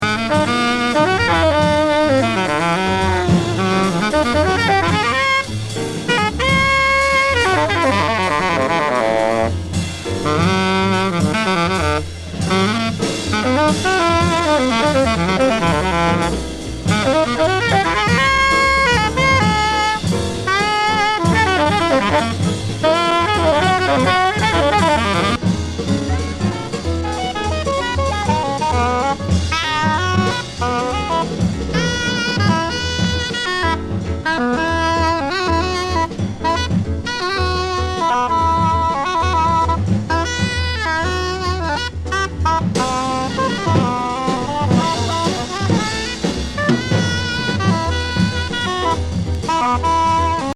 ’65年ニューヨーク録音。片面いっぱい20分超えの熱情スピリチュアル